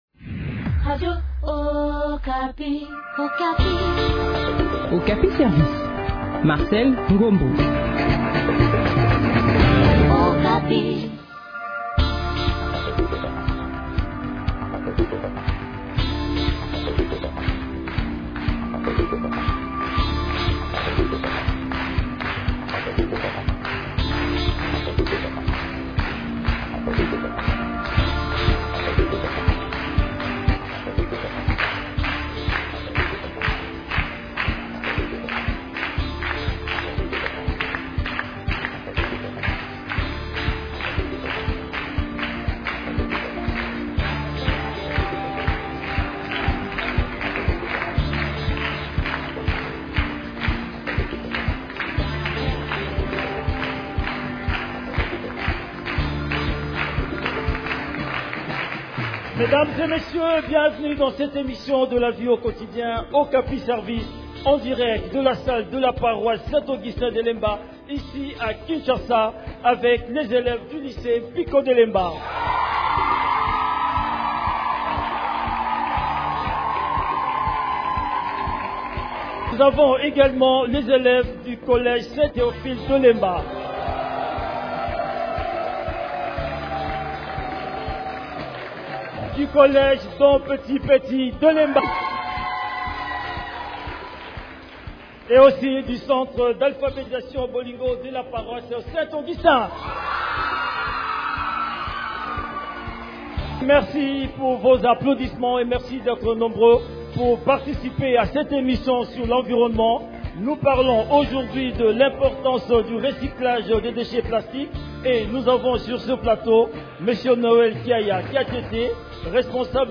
émission grand public organisée dans la salle de promotion de la paroisse Saint Augustin de Lemba (Kinshasa)